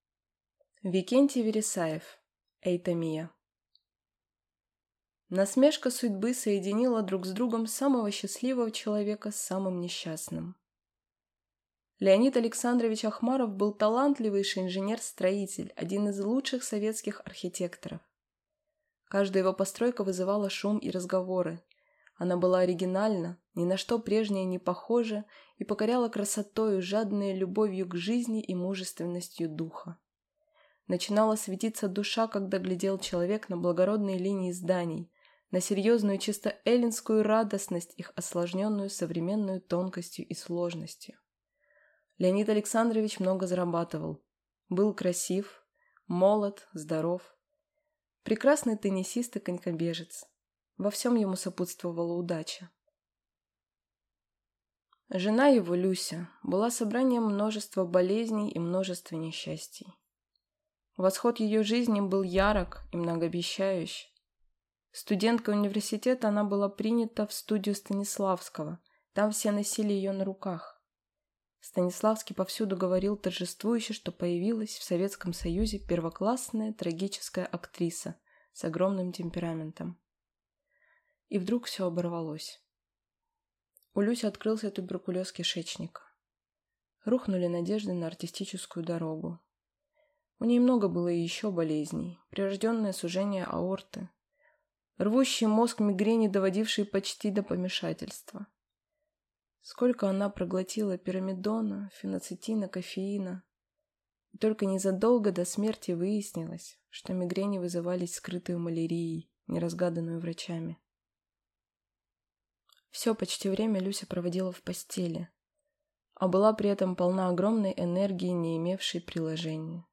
Аудиокнига Euthymia | Библиотека аудиокниг